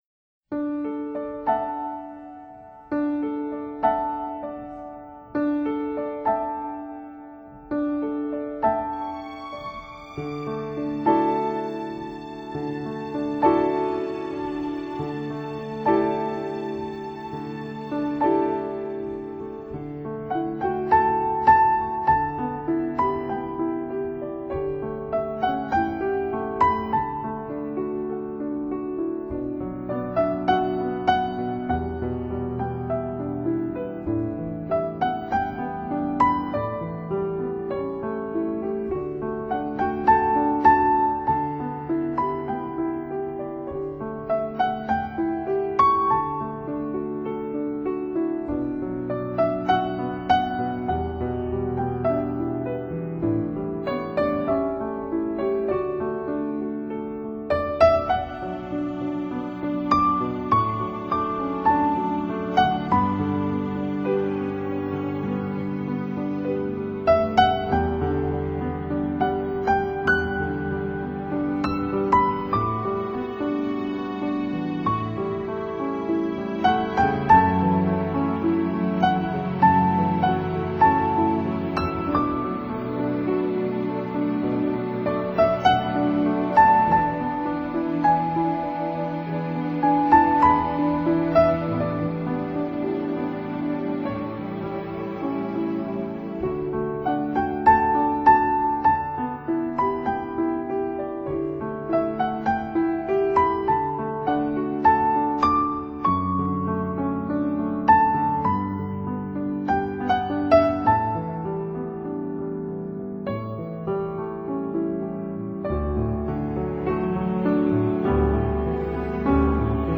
新世紀榜Top20珍藏碟